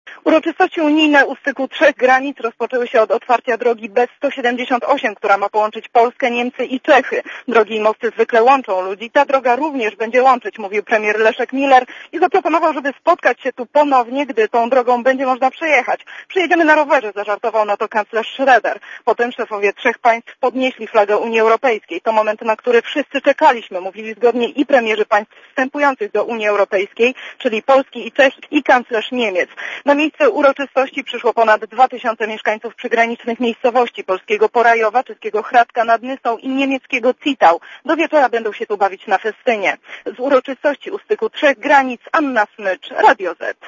Premierzy Polski i Czech oraz kanclerz Niemiec symbolicznie rozpoczęli budowę drogi B-178, łączącej trzy kraje. Uroczystość odbyła się na przedmieściach Zittau.
Posłuchaj relacji reporterki Radia ZET